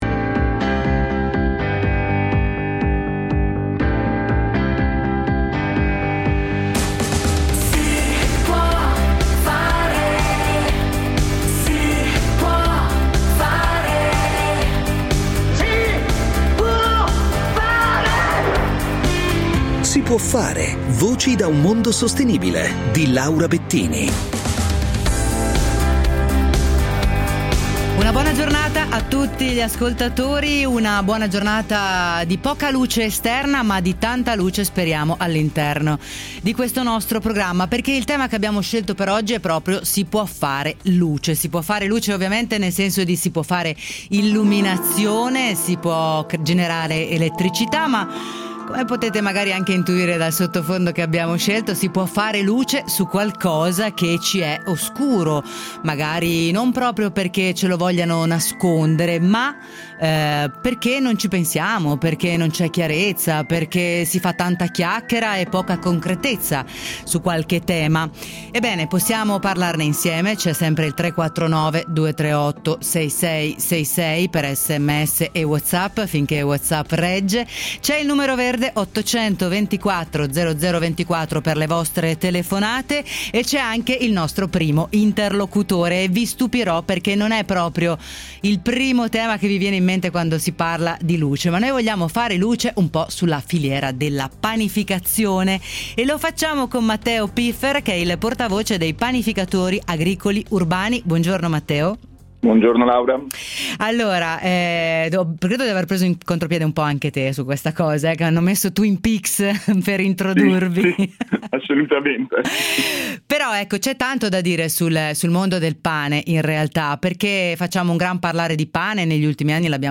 E’ andata in onda sabato 27, alle ore 9.05, l’intervista
sul problema dell’inquinamento luminoso, all’interno del programma “Si può fare”, trasmesso dall’emittente nazionale Radio 24.